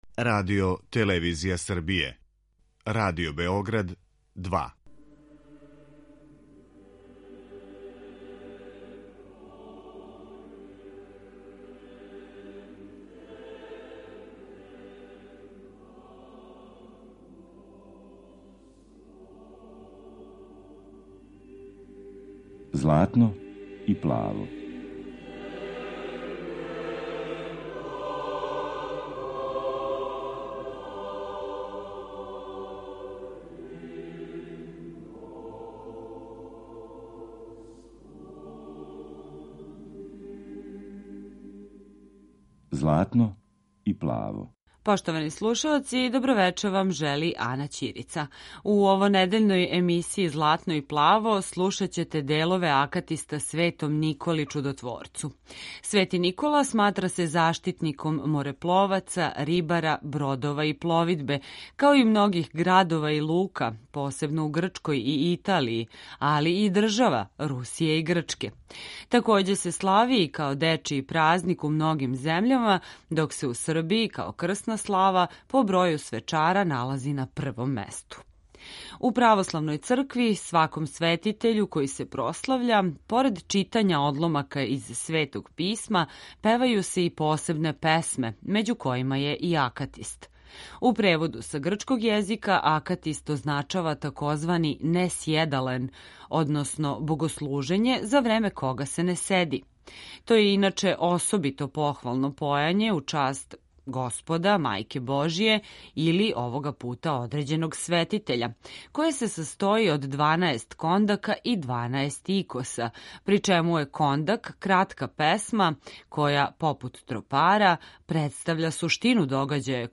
Хор Сретењског манастира
Емисија посвећена православној духовној музици.
Емитујемо делове Акатиста Светом Николи Чудотоворцу, у извођењу хора Сретењског манастира из Москве.